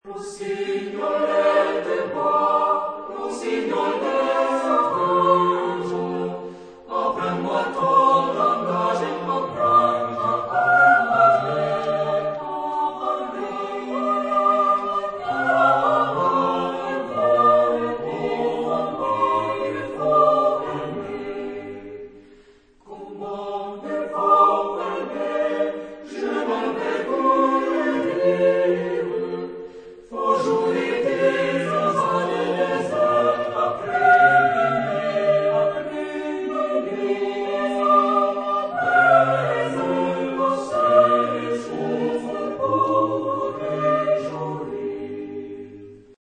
Genre-Style-Forme : Sérénade ; Profane
Caractère de la pièce : allegretto
Type de choeur : SATB  (4 voix mixtes )
Tonalité : sol mineur
Consultable sous : Populaire Francophone Acappella